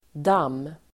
Uttal: [dam:]